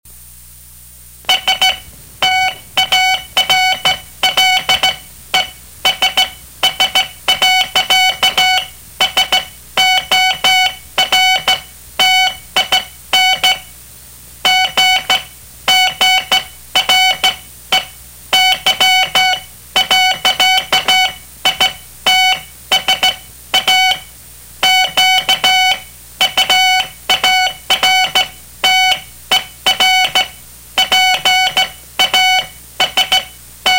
When a caller dialed the number,  It simply played a tape of 3 minutes duration or less  and hung up.
It consisted of 3 minutes of beeping sounds.  Most callers didn't recognize that it was a message in Morse Code.
He borrowed a telegraph machine.